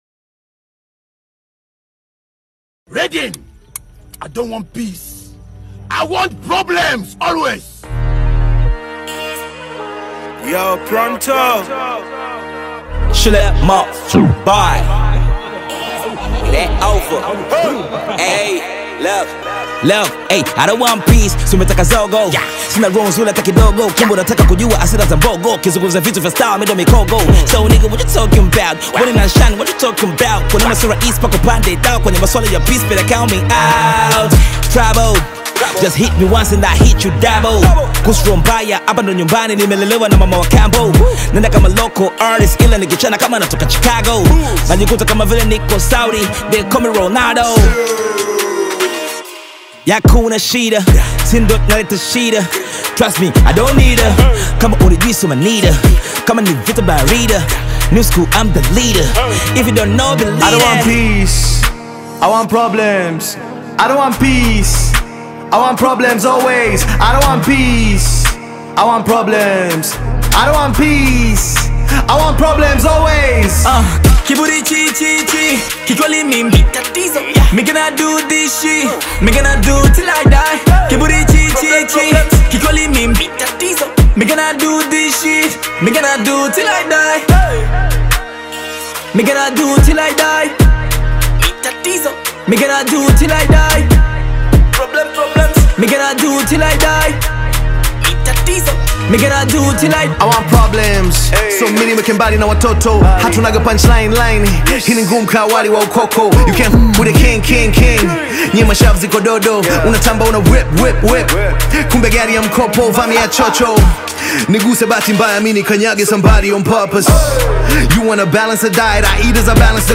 rap song
Tanzanian rap music